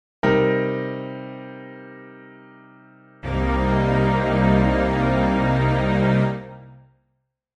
a Cm7 specifically is spelled C   E♭   G   B♭
Click to hear a Cm7 Chord.
c_minor7_chord.mp3